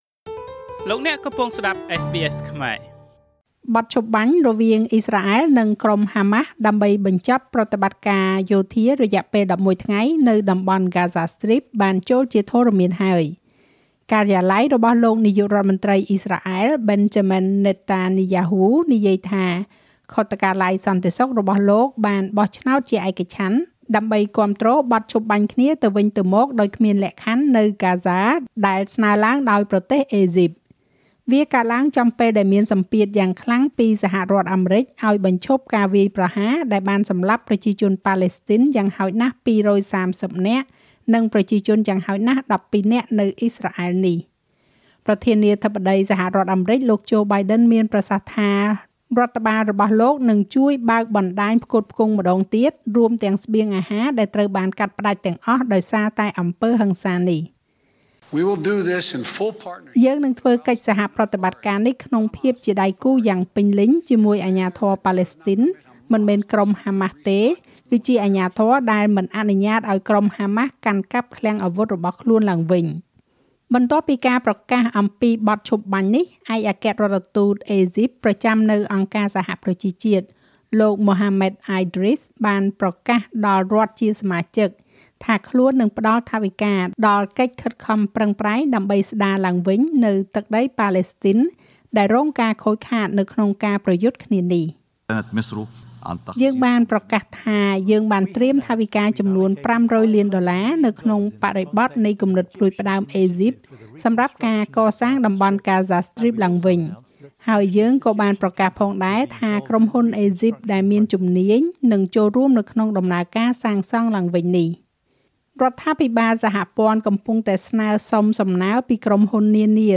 នាទីព័ត៌មានរបស់SBSខ្មែរ សម្រាប់ថ្ងៃសុក្រ ទី២១ ខែឧសភា ឆ្នាំ២០២១